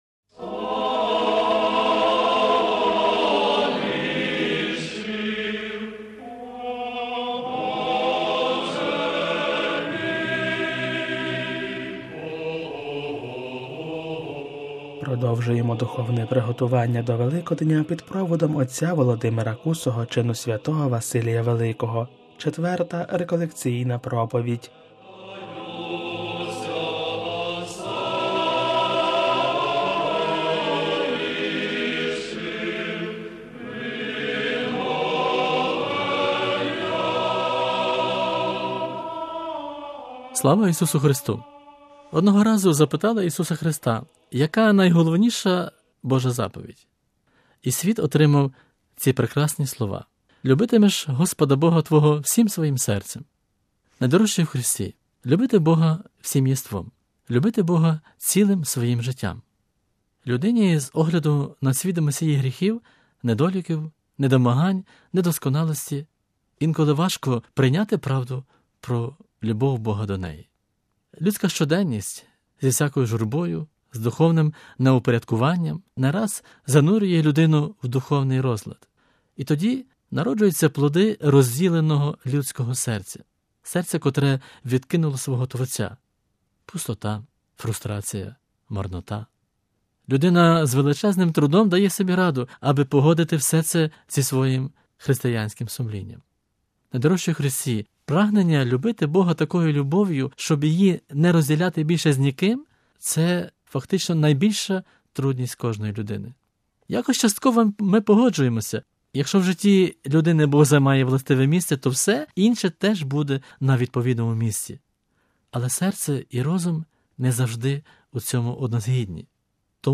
Четверта реколекційна проповідь: All the contents on this site are copyrighted ©.